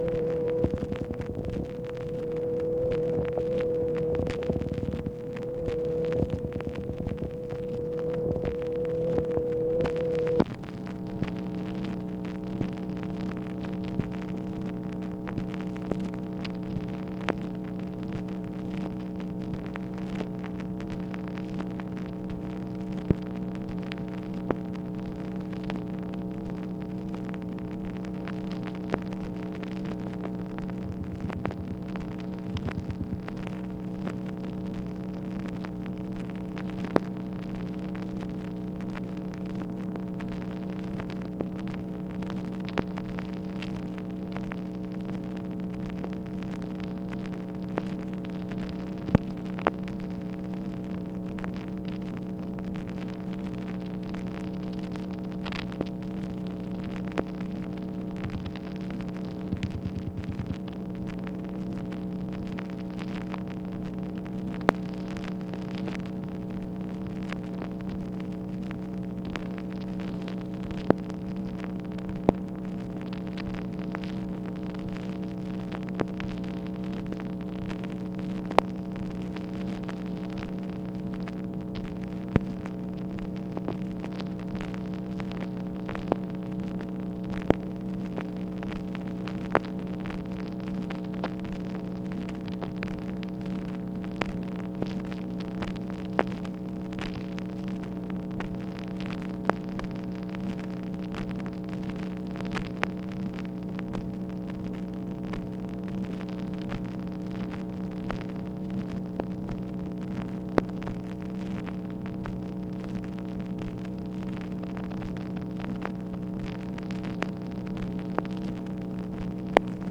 MACHINE NOISE, September 24, 1964
Secret White House Tapes | Lyndon B. Johnson Presidency